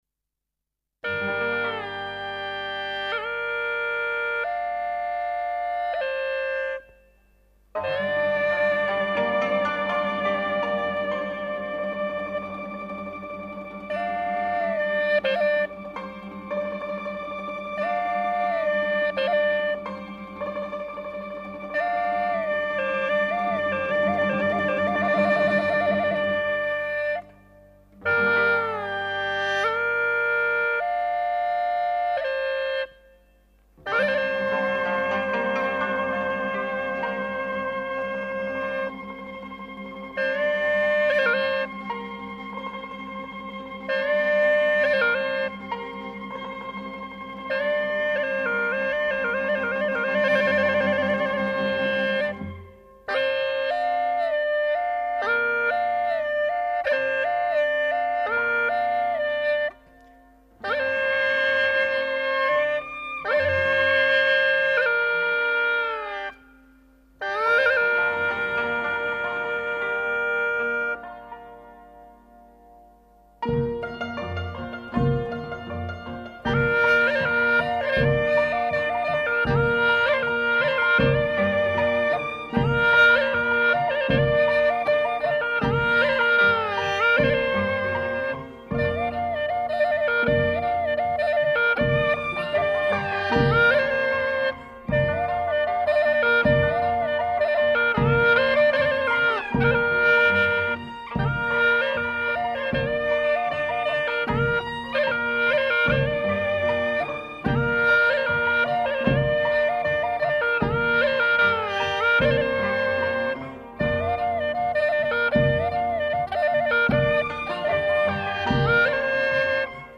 精彩展示六十多种乐器音色，全面感受吹拉弹打缤纷世界！
吹奏乐器1
葫芦丝